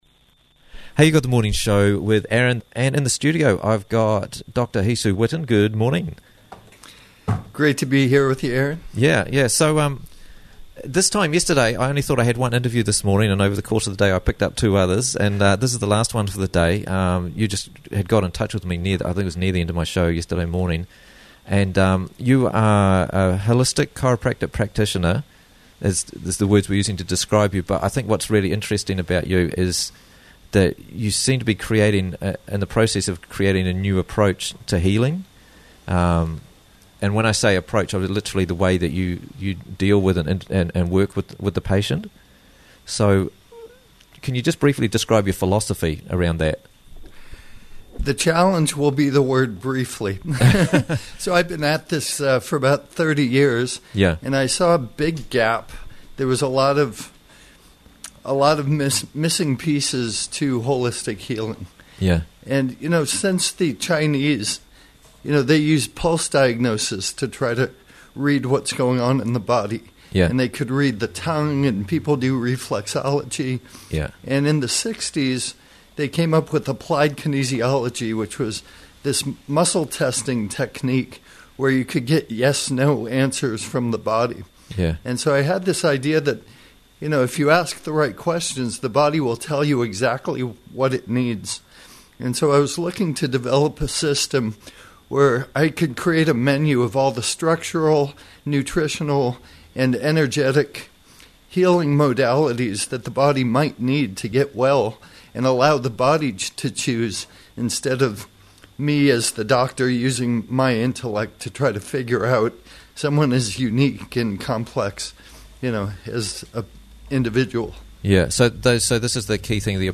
Letting The Body Direct The Healing - Interviews from the Raglan Morning Show